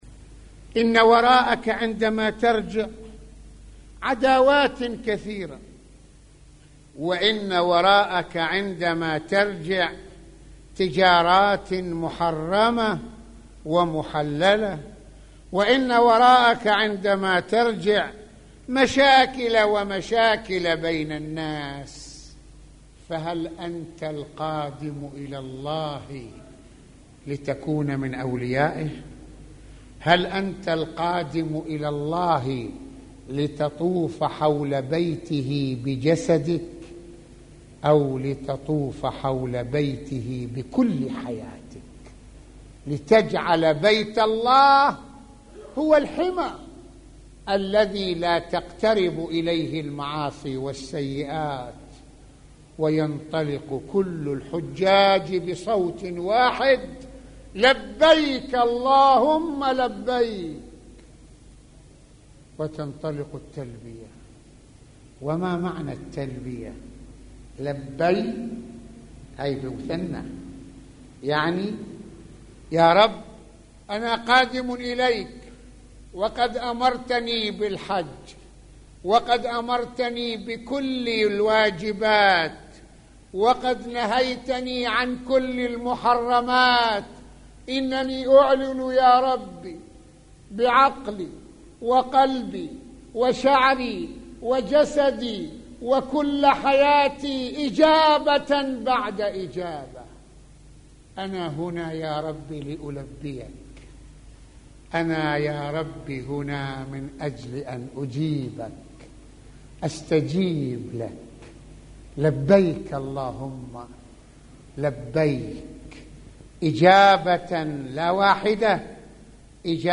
- يتحدث العلامة المرجع السيد محمد حسين فضل الله(رض) في هذه المحاضرة عن مشاكل الناس التي يدلونها عند ذهابهم للحج، وكيفية التعاطي الروحي والنفسي مع قيم الحج ليعتقوا مفهوم الولاية لله تعالى، ويتناول سماحته(رض) شرح مفردات الحج من تلبية وطواف وما ينبغي للإنسان استلهامه...